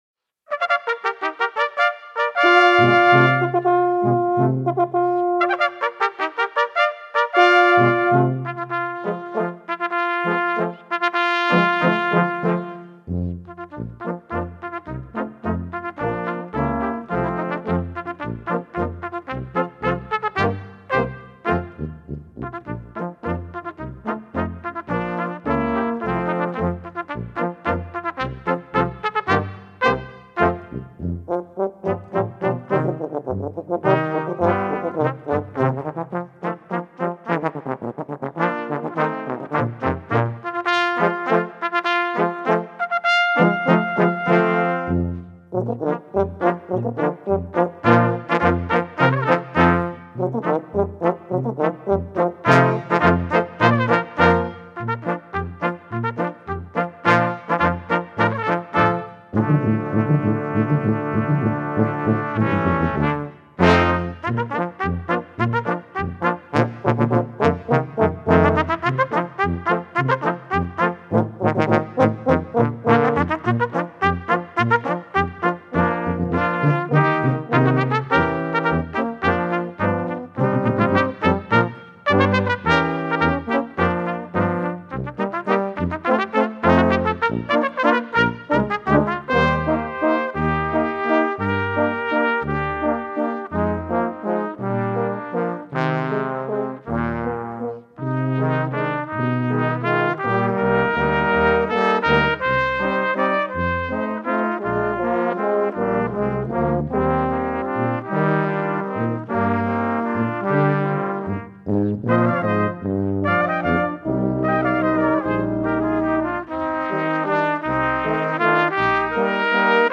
For Brass Quintet